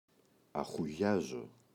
αχουγιάζω [axu’ʝazo]: φωνάζω κάποιον, τον αποπαίρνω.